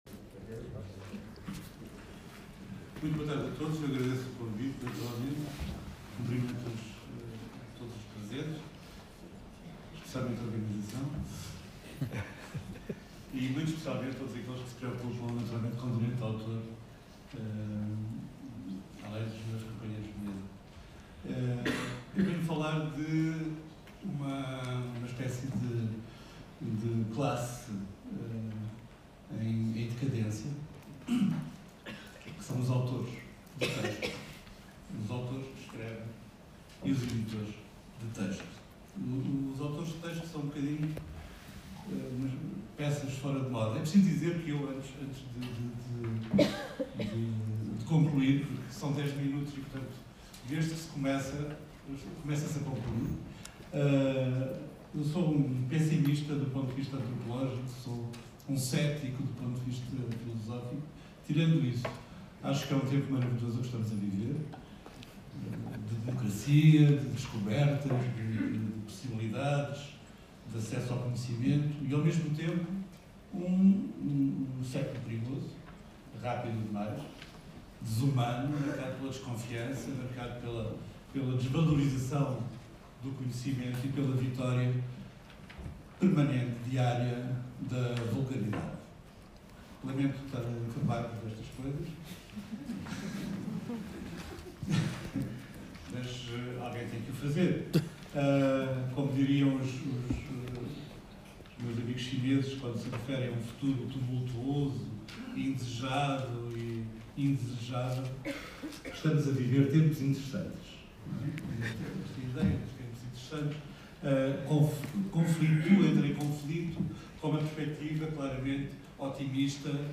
Francisco José Viegas nas Primeiras Jornadas do Tribunal da Propriedade Intelectual, em Lisboa. 2018. FJV fala sobre autores, direitos de autor, livros e muito mais.